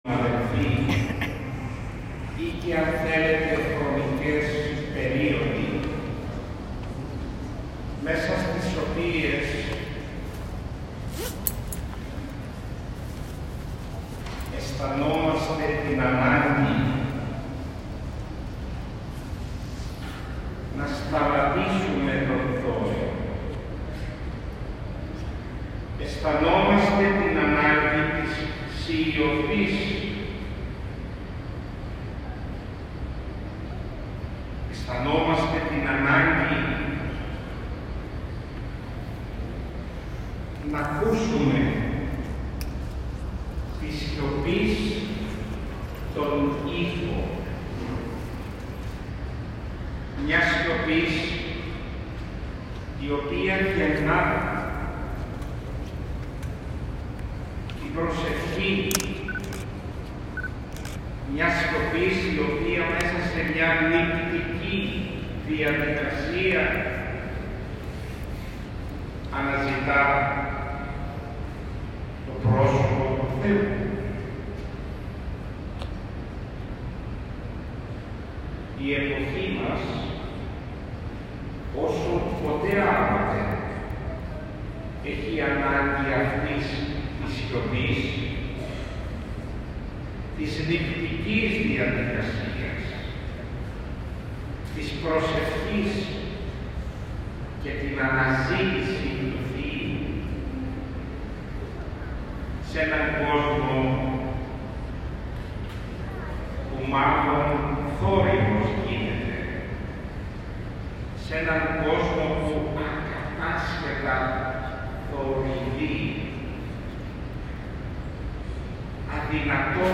Στον Ιερό Ναό Κοιμήσεως της Θεοτόκου στο Ωραιόκαστρο τελέστηκε το απόγευμα της Τρίτης 9 Αυγούστου 2022 η ακολουθία του Μεγάλου Παρακλητικού Κανόνος, χοροστατούντος του Σεβασμιωτάτου Μητροπολίτη Νεαπόλεως και Σταυρουπόλεως κ. Βαρνάβα.